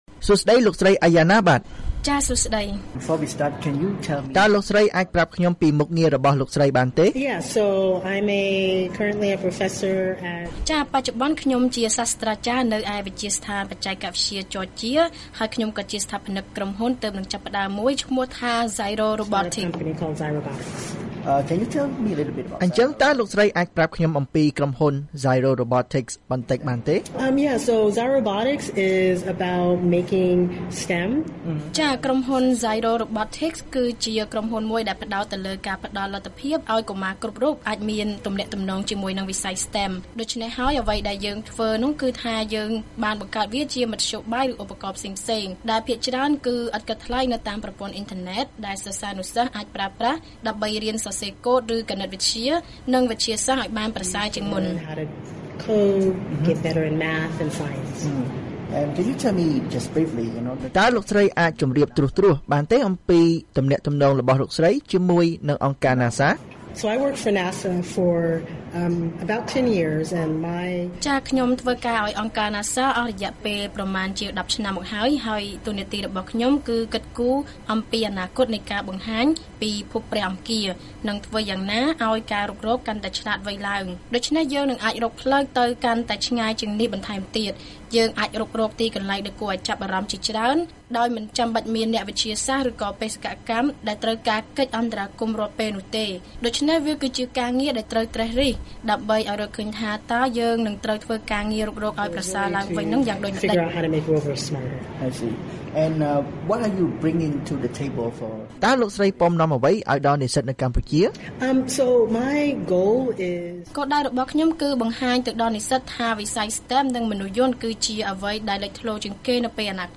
បទសម្ភាសន៍ VOA៖ វិស័យ STEM នៅកម្ពុជានិងសក្តានុពលនៃវិស័យដ៏សំខាន់នេះសម្រាប់កម្ពុជា